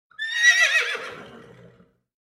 Neighing Of A Horse Bouton sonore